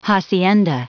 Prononciation du mot hacienda en anglais (fichier audio)
Prononciation du mot : hacienda